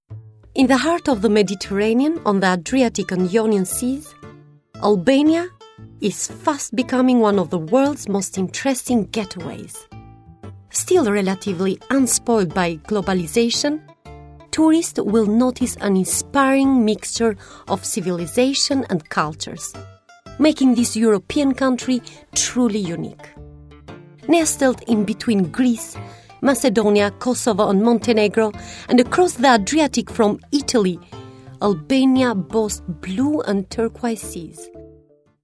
Albanian, Female, 20s-40s